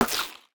UIClick_Menu Select Gravel 02.wav